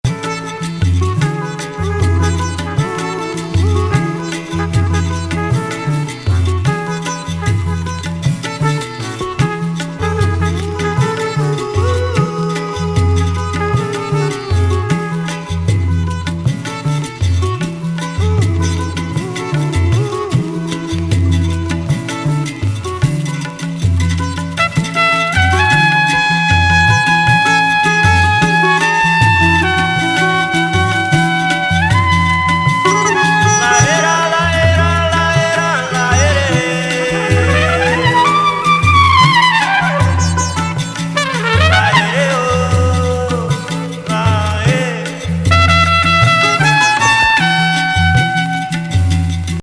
シタール、タブラ、トランペット、ビリンバウの最高峰天界セッション!ECM81年作。